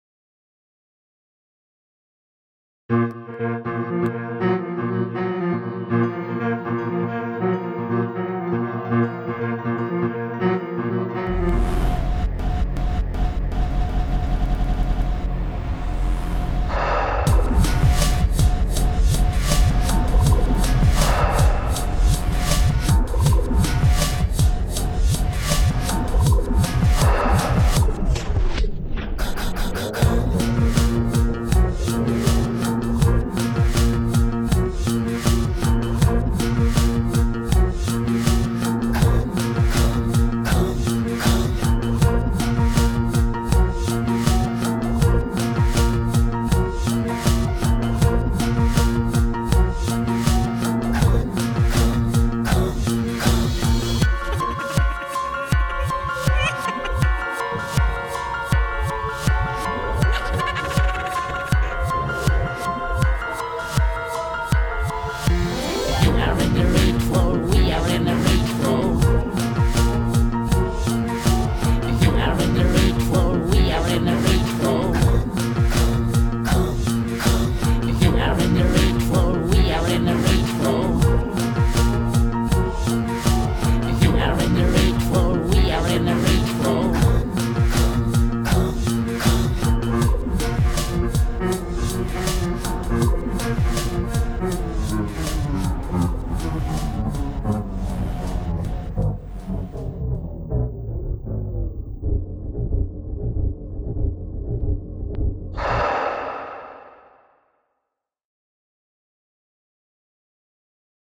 Marrant, la ligne de piano déformée est assez dans le genre de ce que je comptais faire comme musique au départ ^^'
Musique minimaliste et épurée, bon mix, bonne EQ.
allez, comme faut bien que je critique un peu: ton accent anglais est à travailler un peu /tgtlm
On a l'angoisse latente, le coté rythmé-scandé (piano très mécanique et percu).